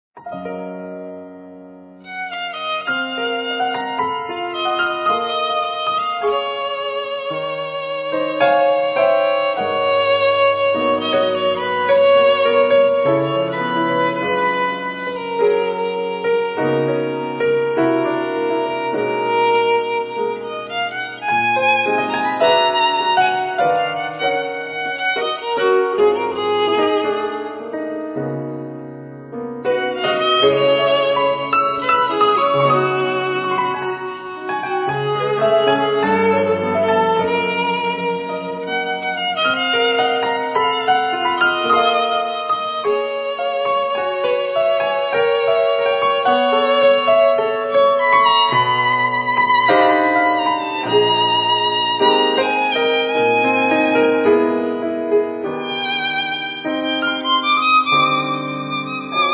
Piano,Keyboards
Alt & soprano sax
Guitars
Violin
Drums
Bass